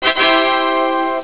tada.au